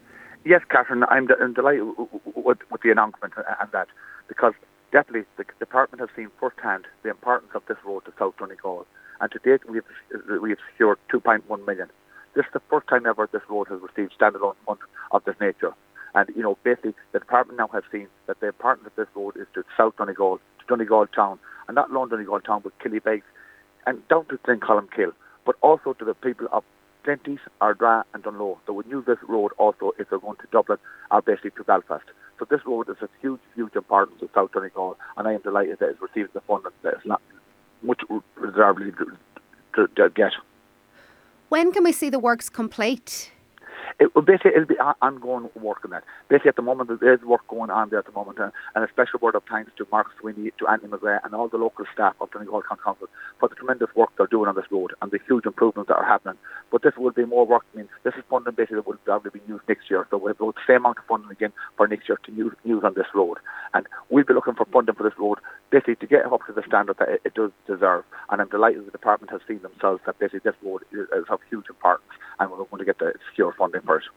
He says once the work is complete, it will make the entire area more accessible: